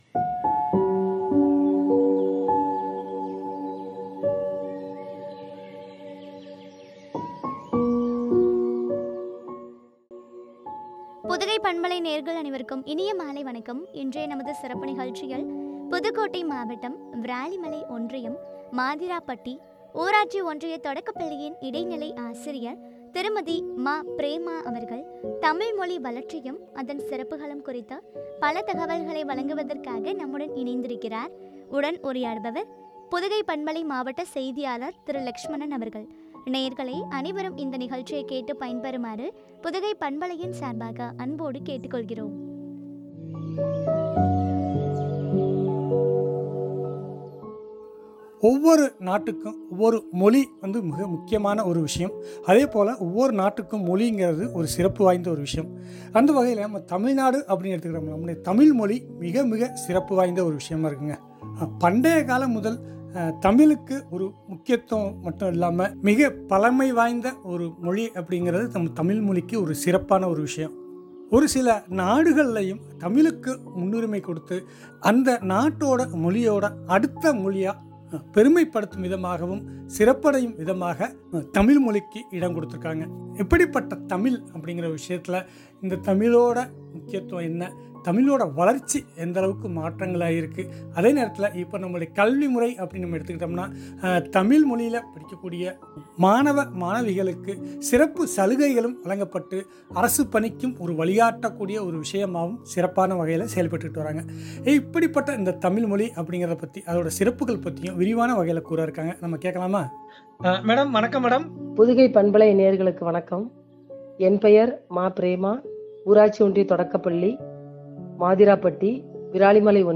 சிறப்புகளும் பற்றிய உரையாடல்.